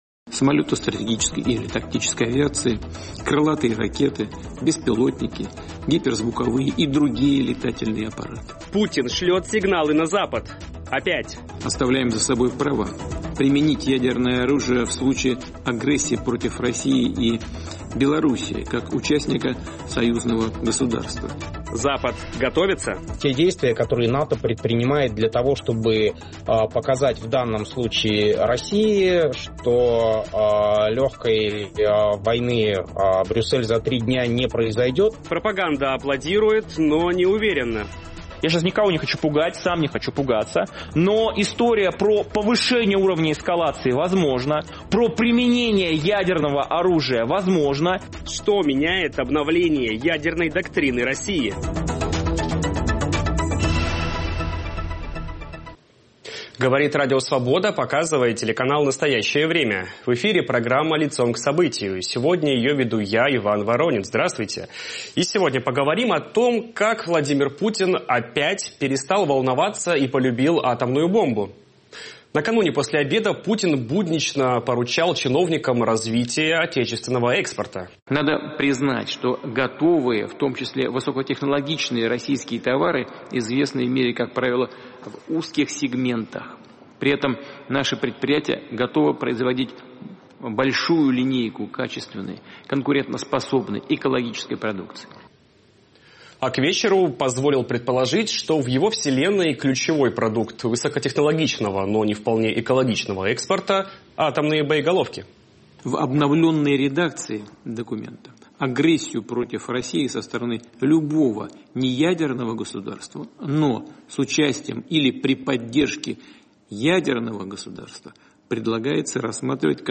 Воспринимают ли союзники Украины путинские страшилки всерьёз? Опасаются ли граждане России начала ядерной войны? Об этом говорим с политиком и блогером Евгением Ступиным.